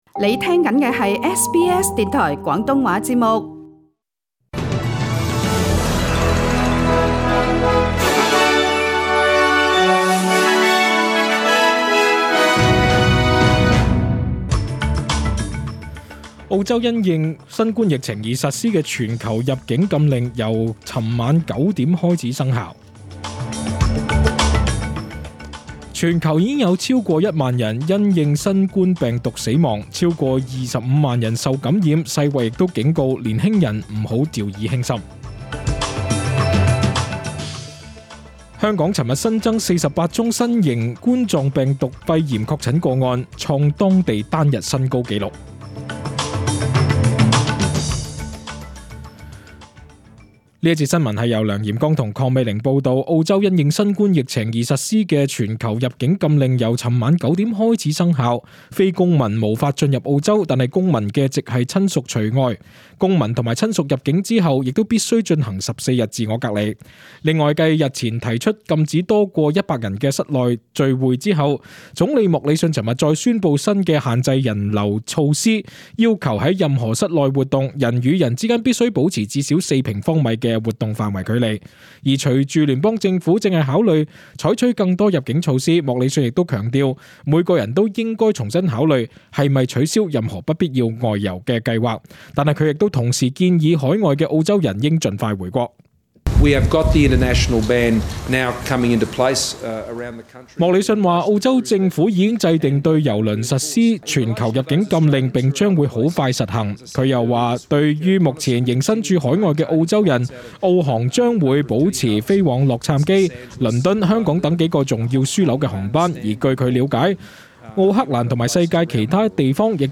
SBS中文新闻 （三月二十一日）
请收听本台为大家准备的详尽早晨新闻。